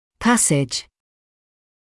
[‘pæsɪʤ][‘пэсидж]проход; переход; проведение